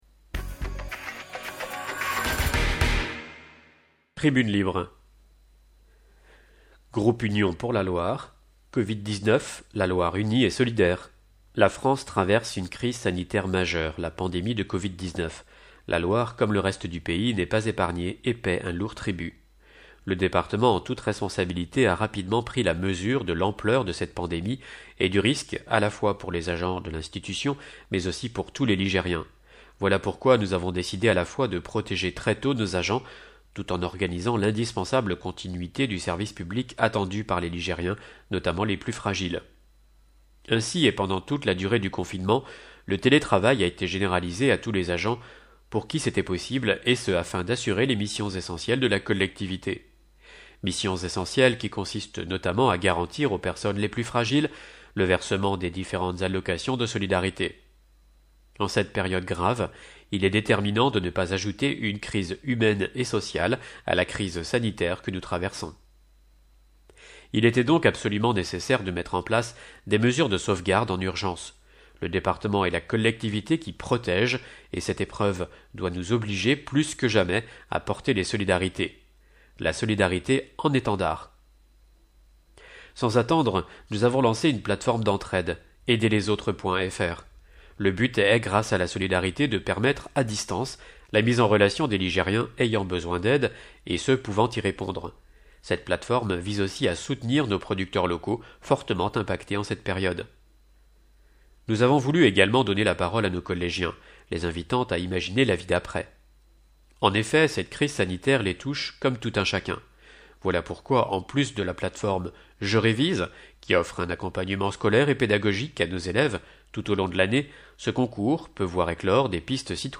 Loire Magazine n°141 version sonore